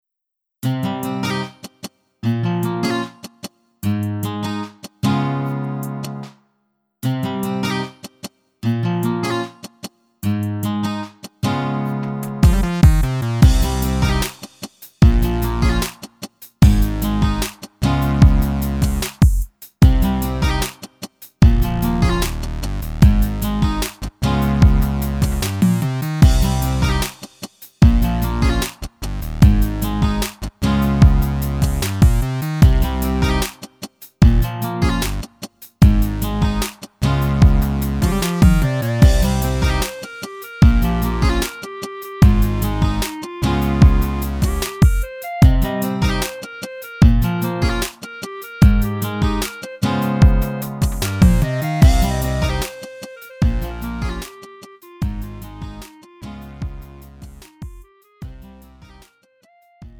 음정 원키
장르 구분 Lite MR